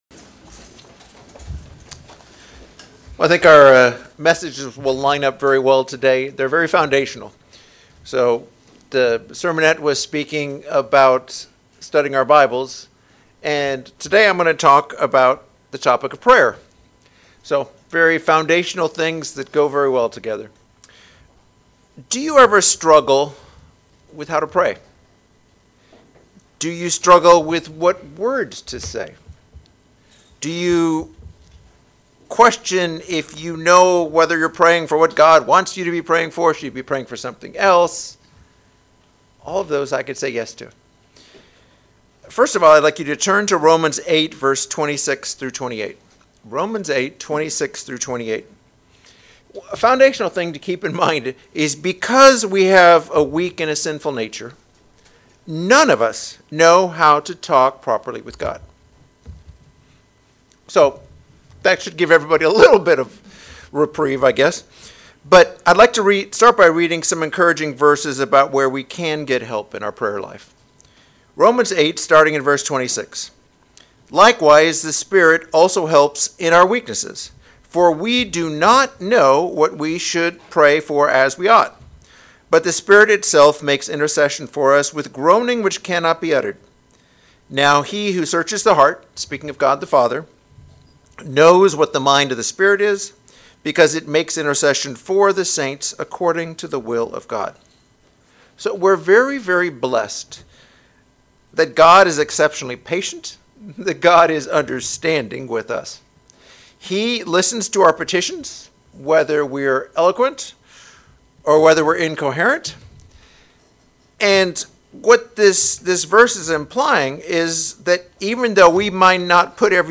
This message provides 5 lessons of what to pray for based on scriptures that say "pray that" or "pray for" Note: This is an audio only sermon.